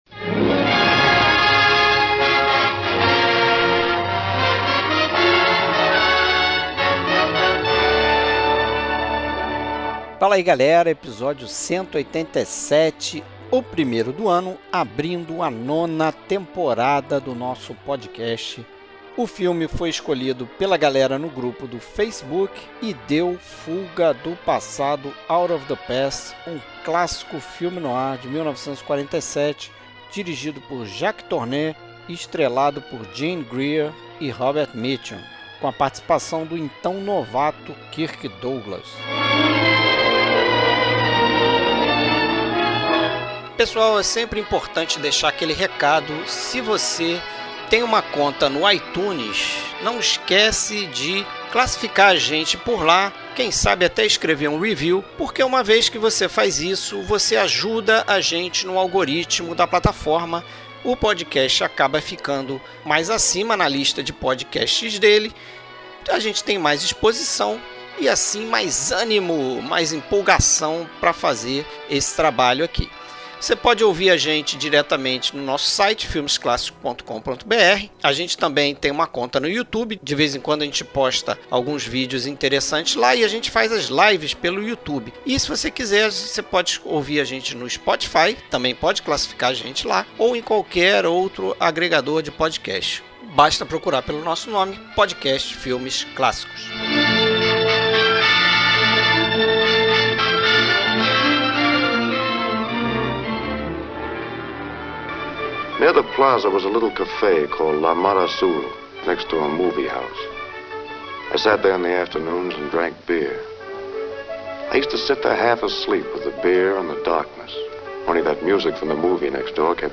Trilha Sonora: Músicas compostas para este filme e outros do subgênero.